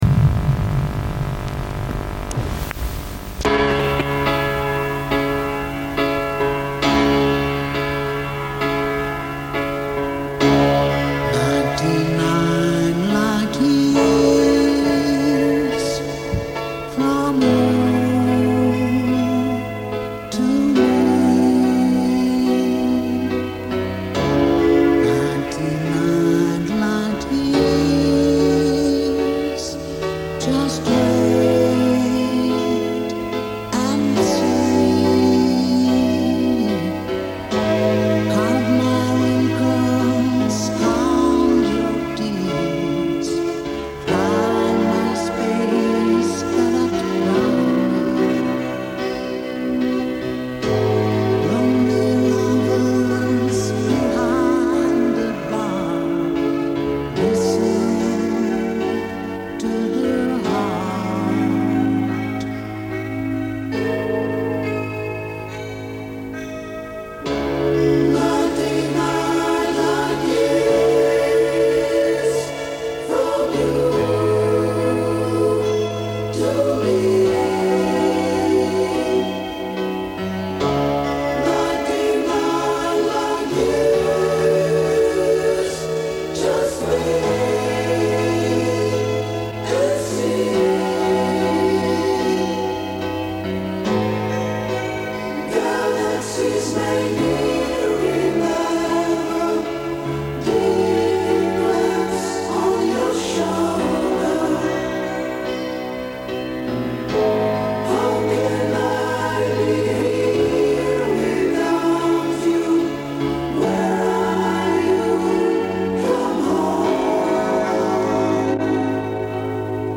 Судя по акценту,это чехи середины восьмидесятых.
keyboard instruments
drums
bass guitar
electric guitar